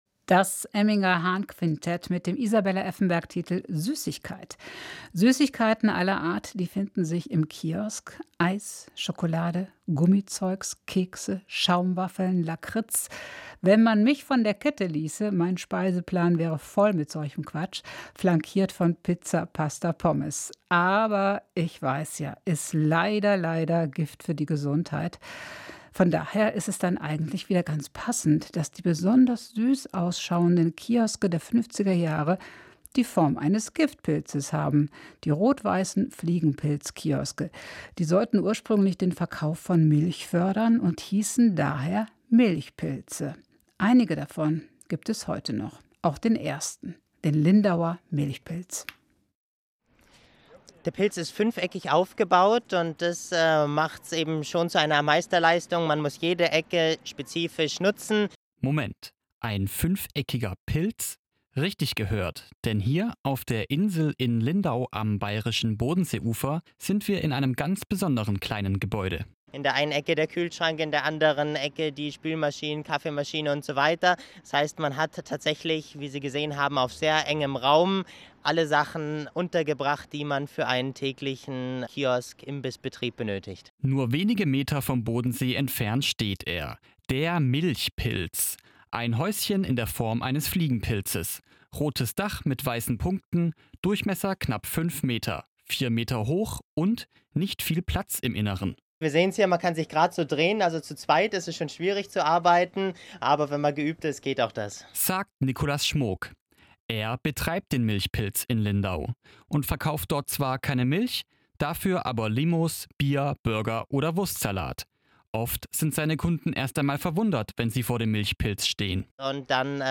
Milchpilz für die Ohren - Radiobeitrag des SWR